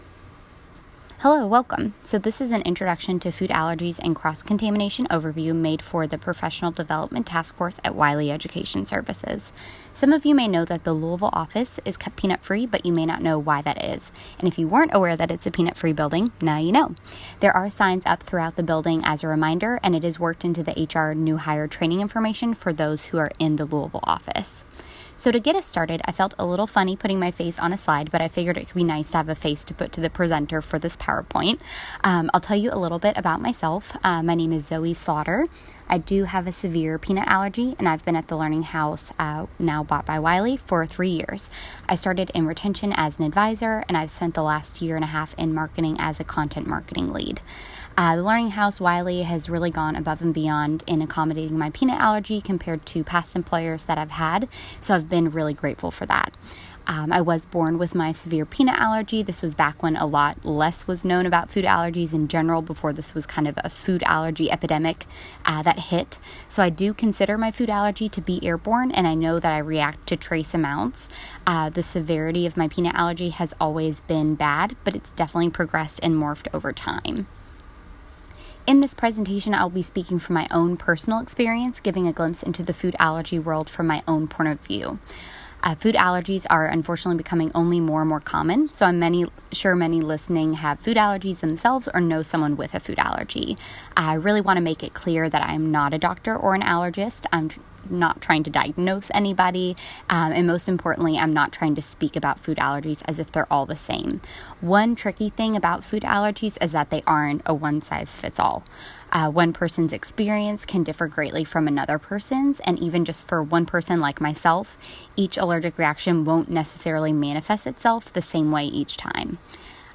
It was remote and open to all 500+ employees, and I focused on food allergy awareness & ways people can help support those with life-threatening food allergies.